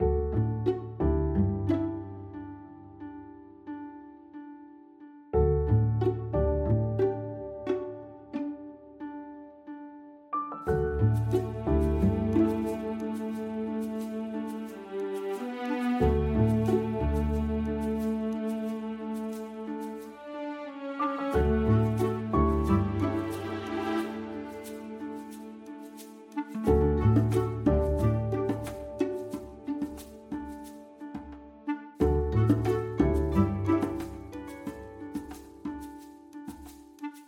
یک موزیک پس زمینه برای بازی نیاز داریم. آهنگ مورد نظر ما یک سری بیپ هایی دارد که خوشایند نیست و می خواهیم موزیکی در این راستا بدون آن عناصر تولید شود.